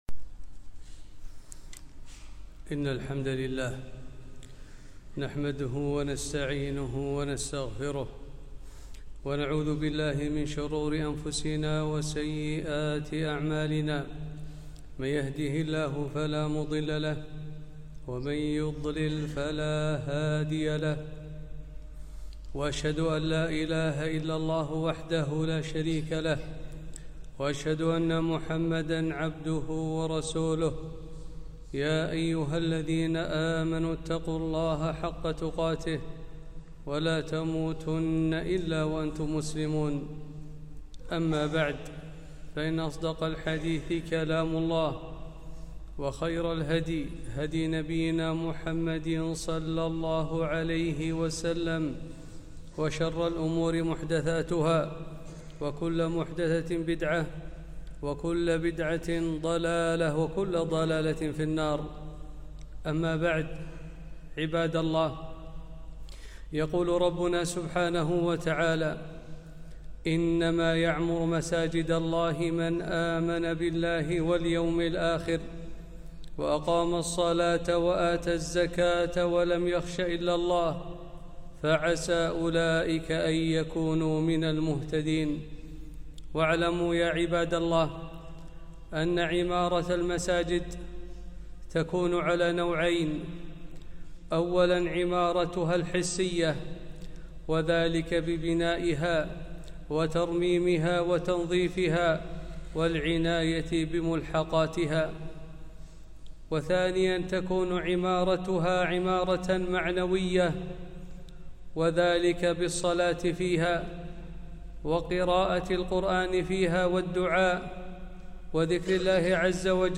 خطبة - آداب المساجد